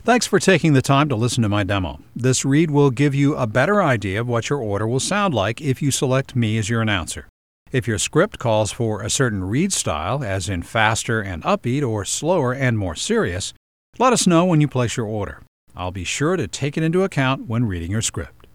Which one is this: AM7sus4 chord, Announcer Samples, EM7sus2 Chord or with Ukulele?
Announcer Samples